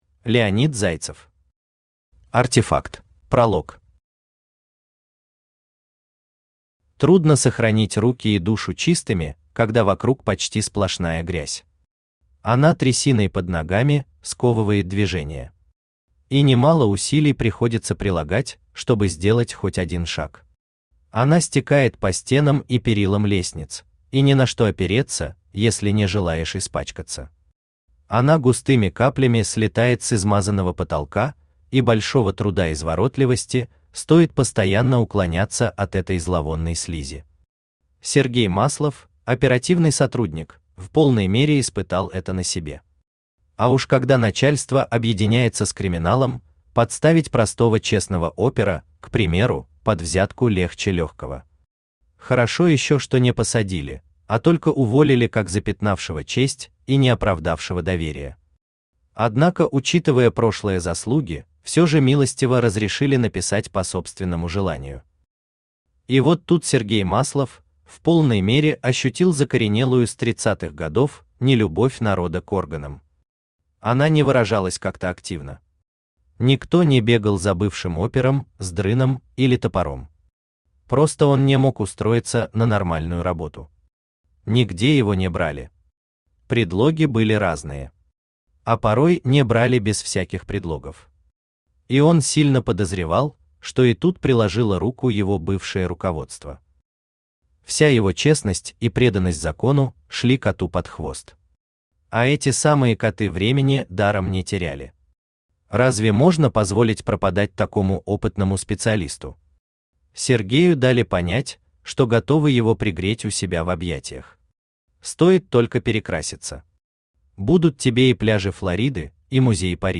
Аудиокнига Артефакт | Библиотека аудиокниг
Aудиокнига Артефакт Автор Леонид Зайцев Читает аудиокнигу Авточтец ЛитРес.